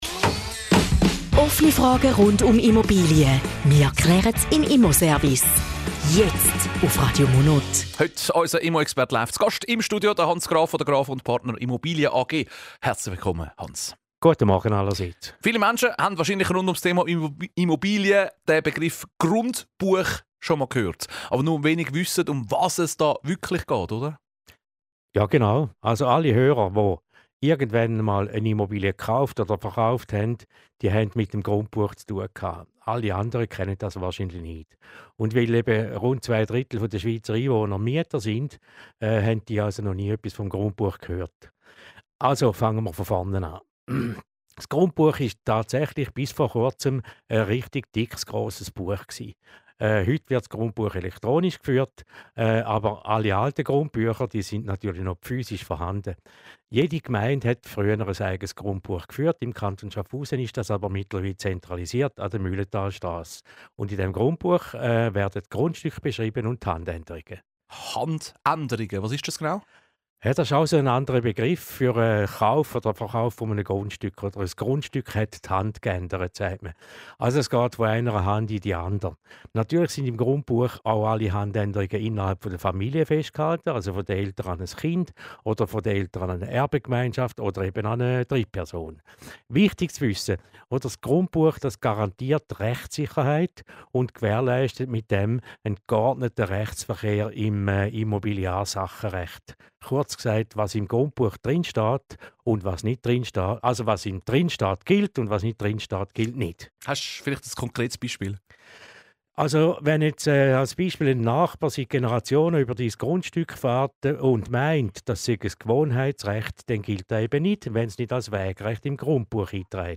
Zusammenfassung des Interviews zum Thema "Das Grundbuch - ein Erfolgsmodell":